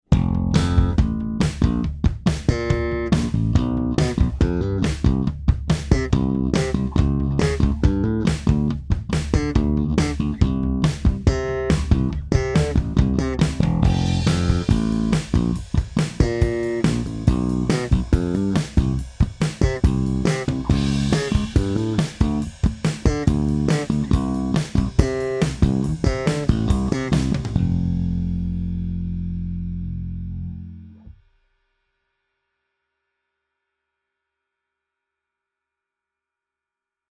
s kompresorem:
Slap - wet